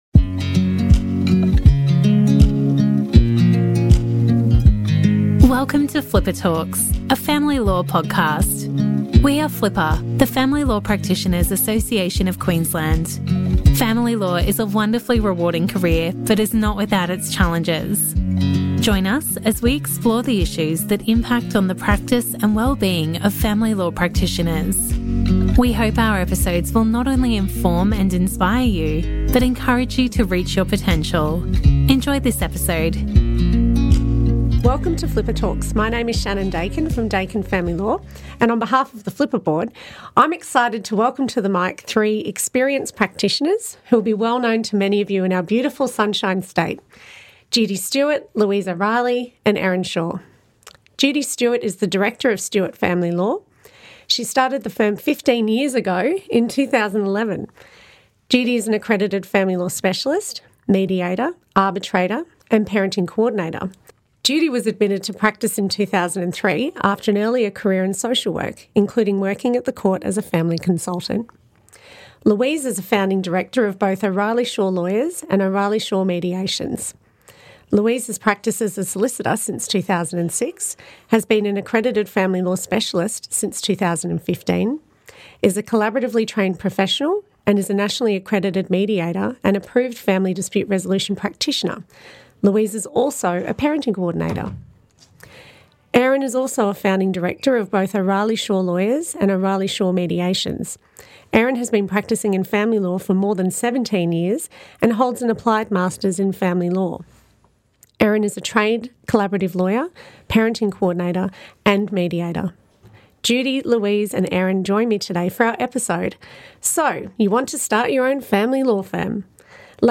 The conversation covers the challenges and rewards of going out on your own, practical insights from the transition, and the lessons they have learned along the way - including some of their personal keys to success.